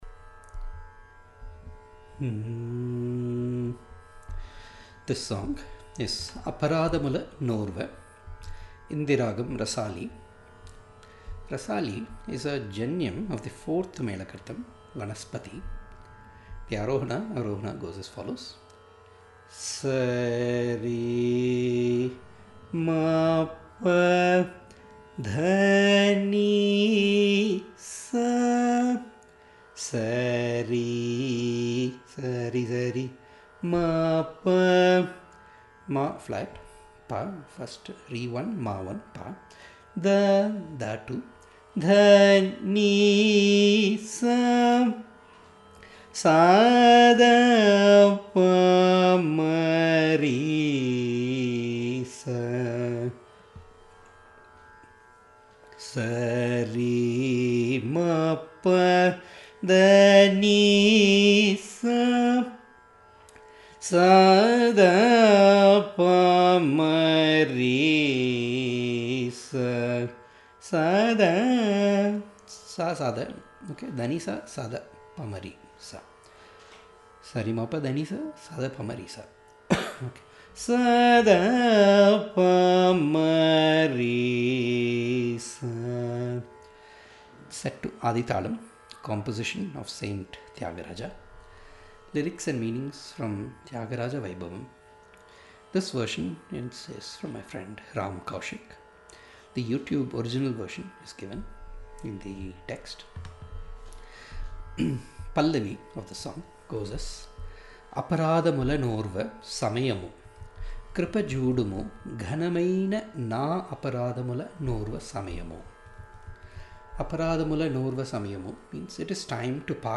RAGAM- rasAli (4th Melakartha Vanaspati Janyam)
South Indian Classical (Carnatic) Music Archive: Classes / Lessons
aparadhamula-rasali-class.mp3